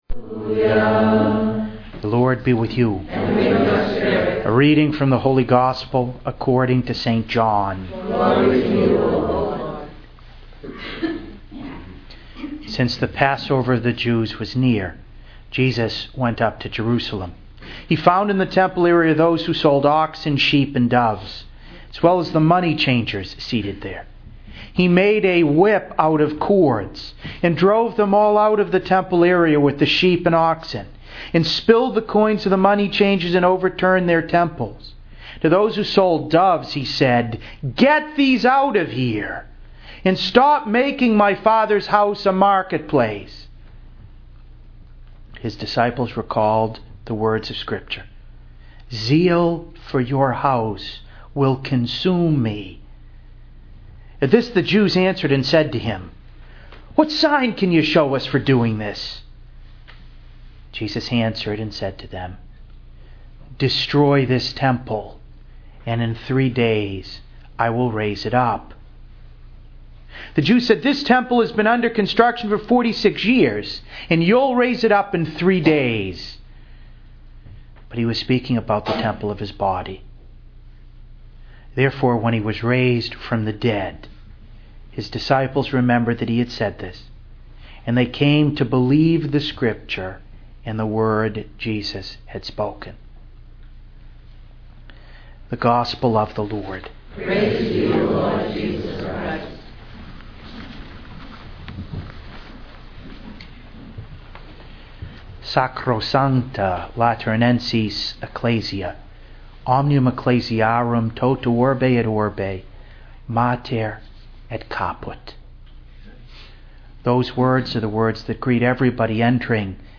To listen to an audio recording of this homily please click here: